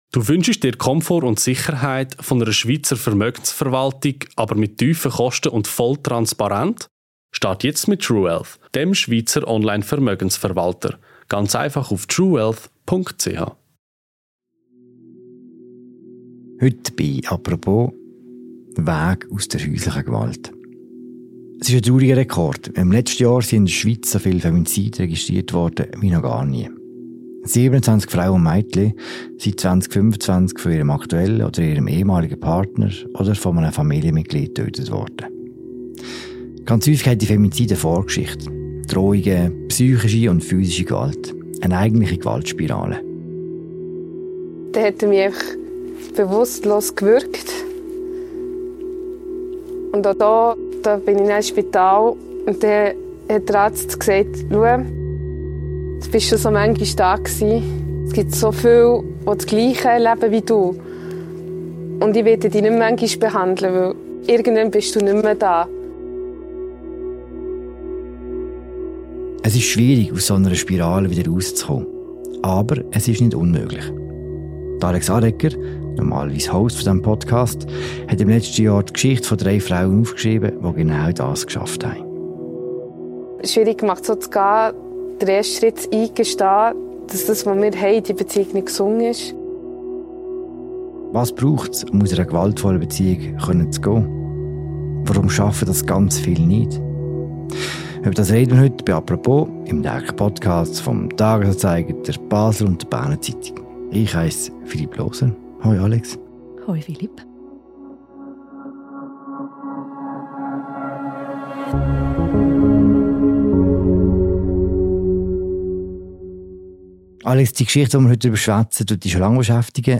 Zudem schildern zwei betroffene Frauen ihre persönlichen Erfahrungen mit häuslicher Gewalt – und wie sie den Weg hinaus gefunden haben.